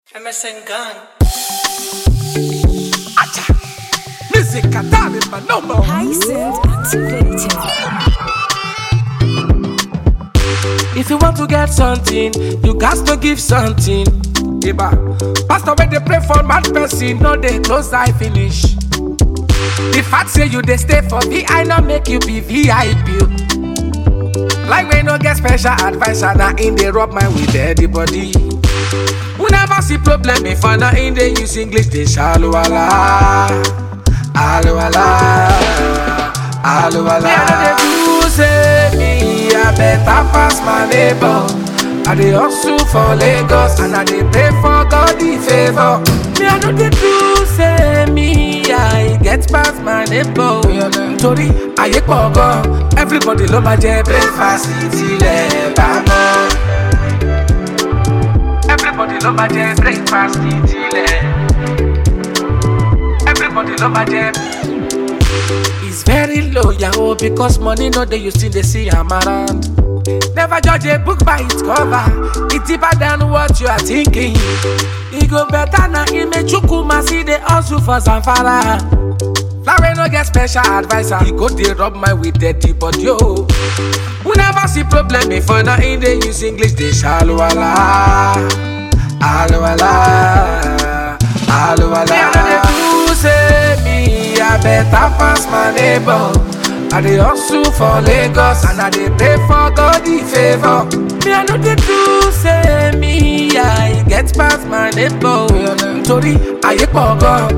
Afro-beat single influenced with good beats and lyric flows